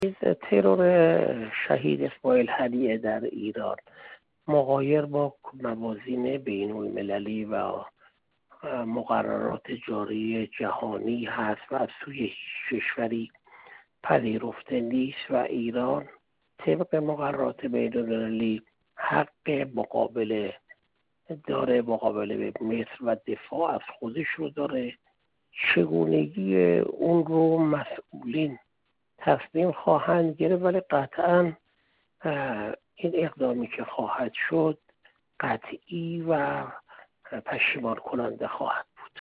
محسن پاک‌آیین، دیپلمات پیشین کشورمان
گفت‌وگو